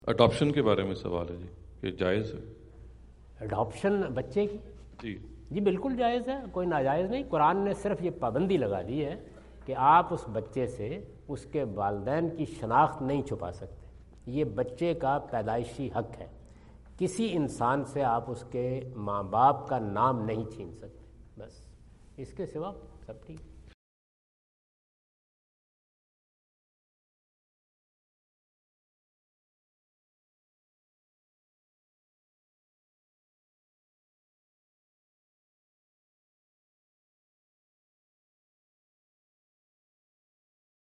Javed Ahmad Ghamidi answer the question about "Adoption" asked at Aapna Event Hall, Orlando, Florida on October 14, 2017.
جاوید احمد غامدی اپنے دورہ امریکہ 2017 کے دوران آرلینڈو (فلوریڈا) میں "بچہ گود لینا" سے متعلق ایک سوال کا جواب دے رہے ہیں۔